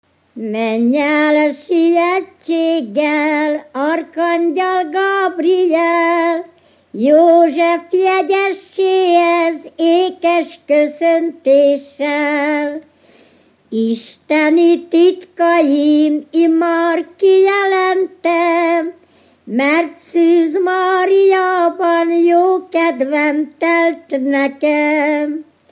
Felföld - Bars vm. - Barslédec
Műfaj: Egyházi népének
Stílus: 8. Újszerű kisambitusú dallamok
Szótagszám: 6.6.6.6
Kadencia: 1 (2) 2 1